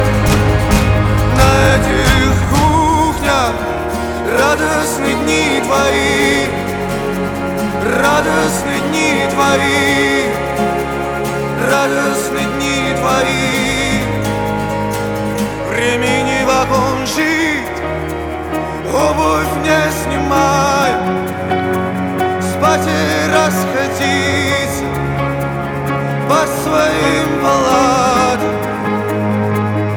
Alternative Indie Rock